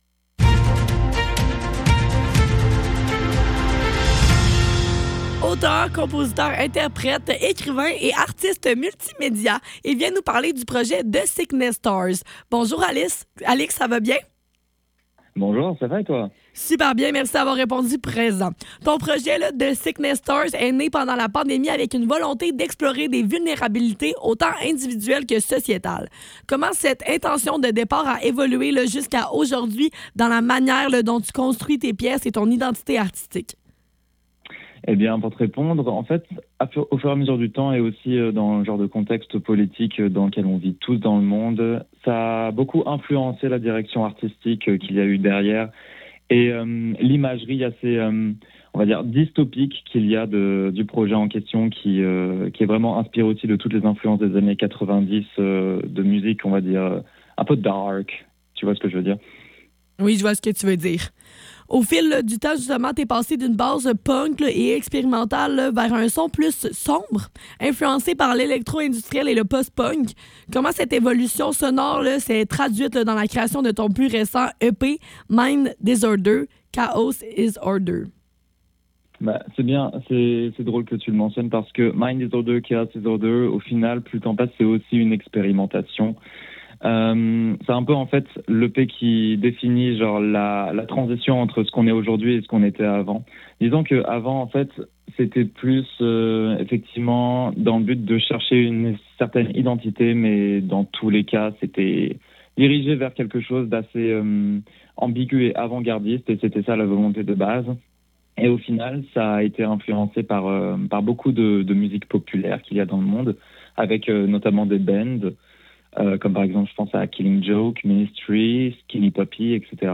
Le Neuf - Entrevue avec The Sickness Stars - 26 mars 2026